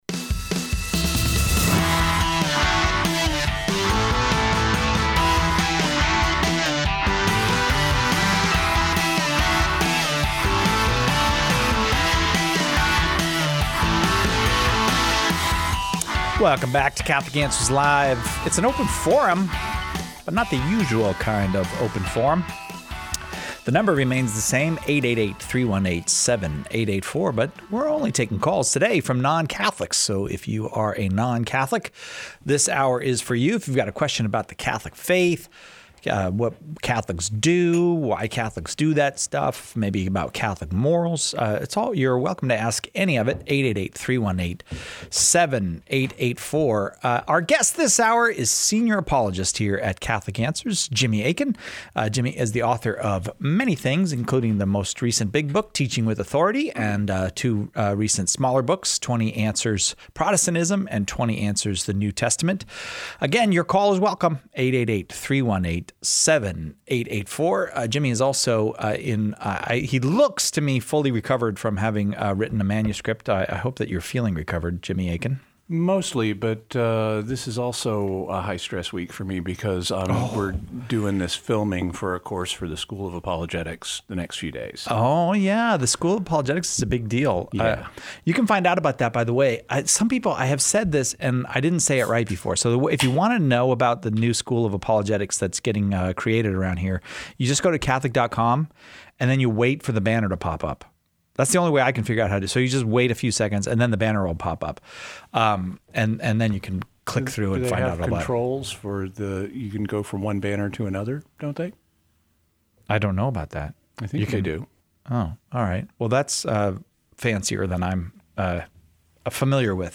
From those who oppose the Catholic Church to those who are thinking about entering, in this show, we take a break from talking with Catholics to answer question...